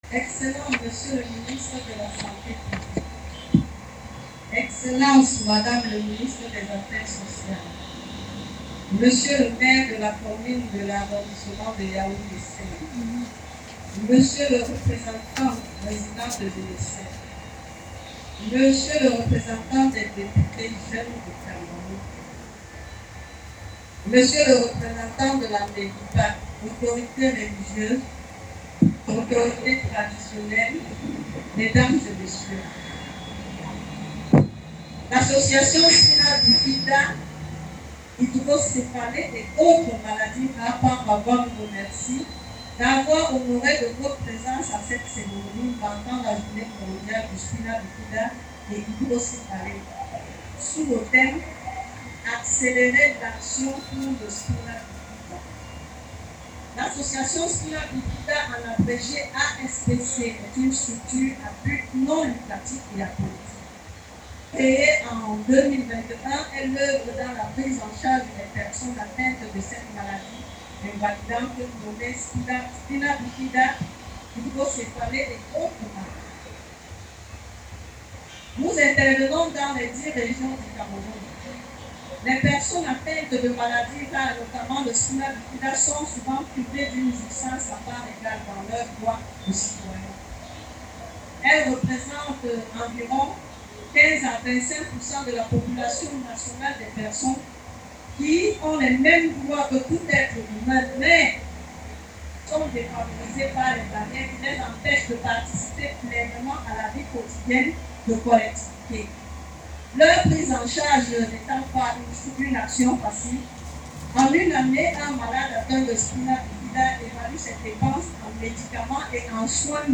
La cour de l’école maternelle Notre-Dame des Victoires de Mvog-Ada, à Yaoundé, a accueilli une cérémonie à la fois sobre et profondément humaine.
Au fil des échanges, la parole a circulé entre professionnels de santé, parents et responsables associatifs. Des interventions pédagogiques ont permis de mieux comprendre cette malformation congénitale, ses conséquences et l’importance d’une prise en charge précoce.